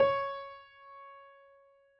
piano_061.wav